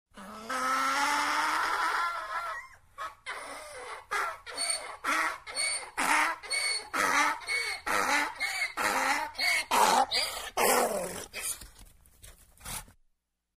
Звуки осла
Ревёт